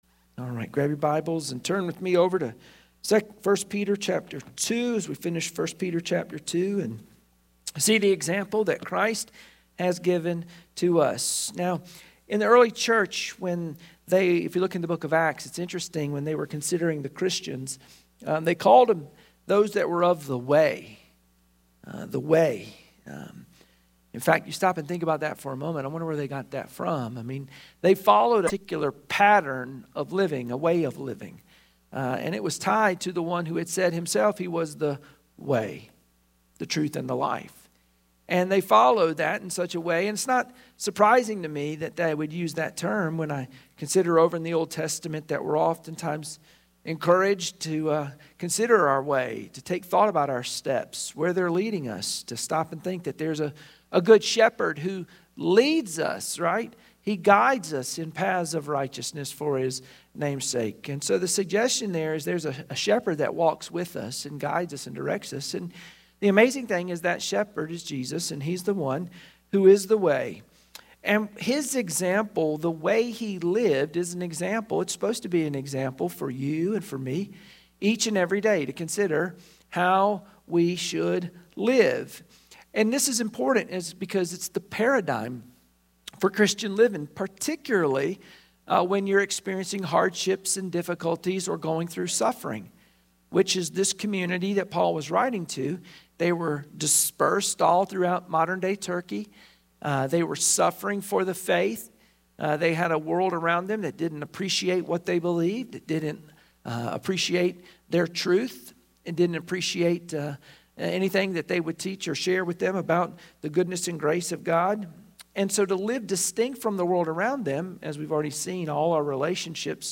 1 Peter 2:21-25 Service Type: Wednesday Prayer Meeting Share this